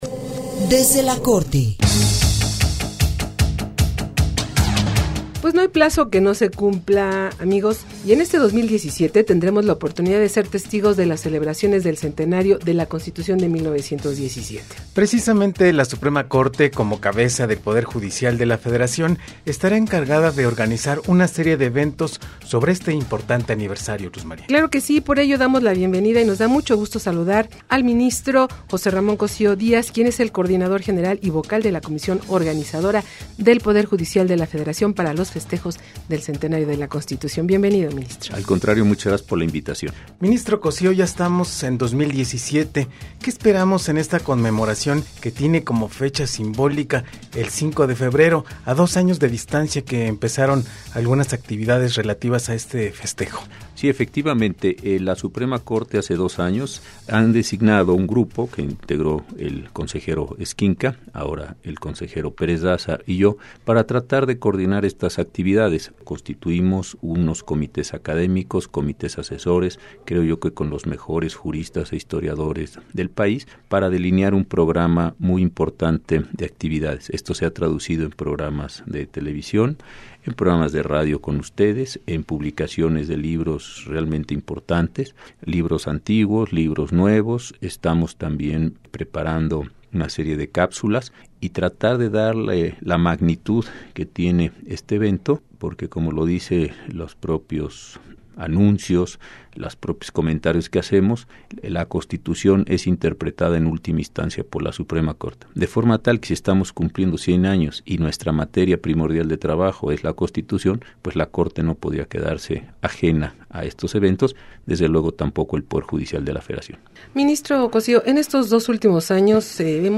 Entrevista 1 con el Ministro José Ramón Cossío Díaz - Anuncio del inicio del Programa de Trabajo para los festejos de la CPEUM
3ENTREVISTA_MINISTRO_JRCOSSIO.mp3